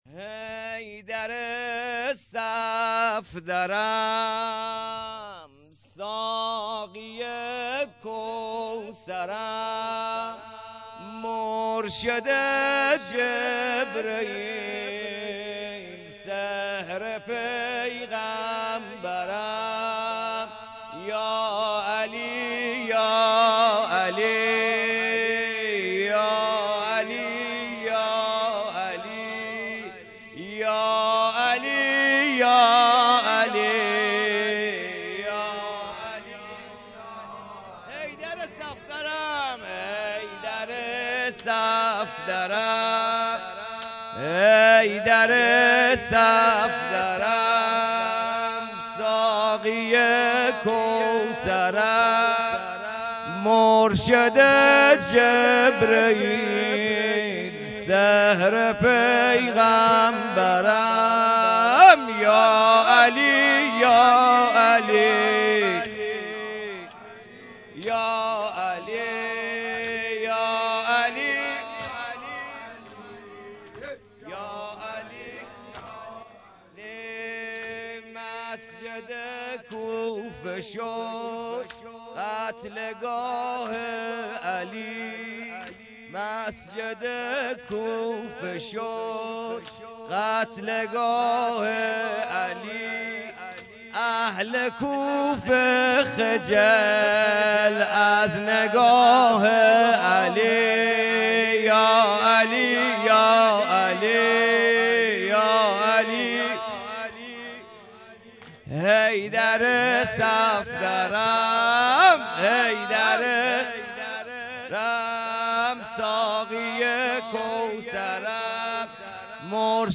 زمینه قدیمی عالی
لیالی قدر رمضان ۹۸